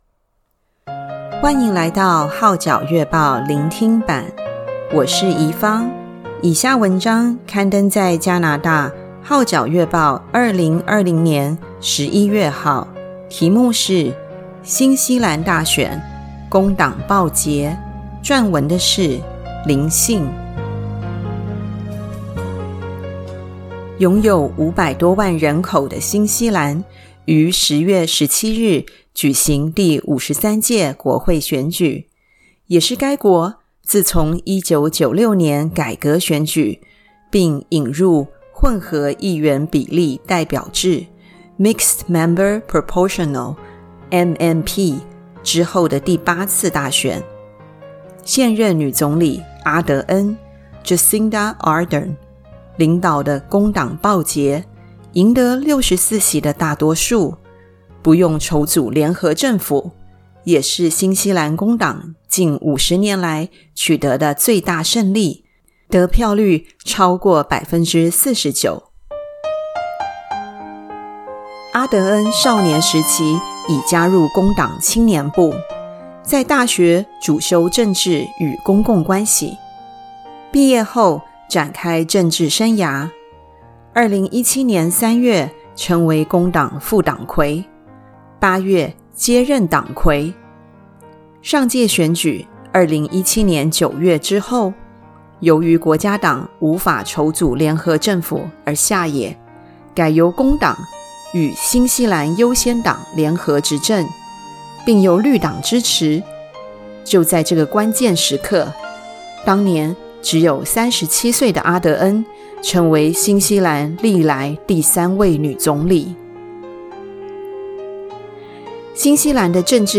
聆聽版/Audio新西蘭大選 工黨報捷
新西蘭大選 工黨報捷 下載粵語MP3檔案